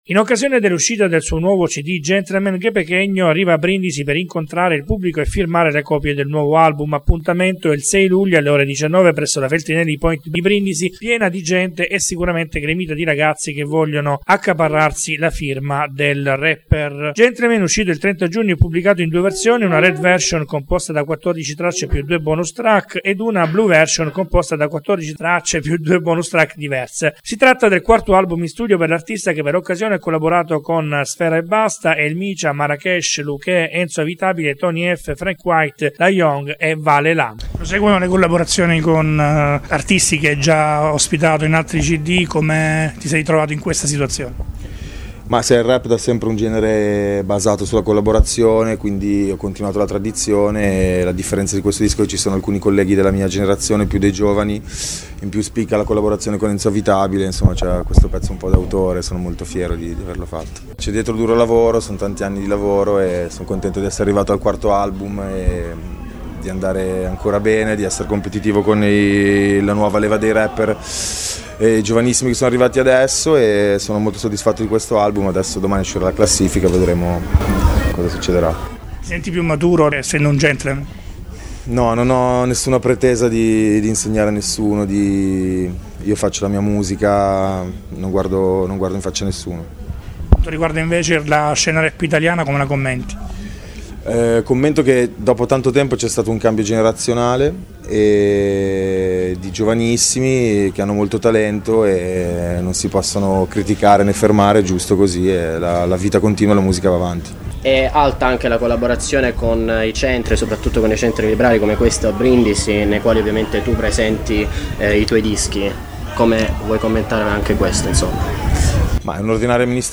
Intervista_GuePequeno.mp3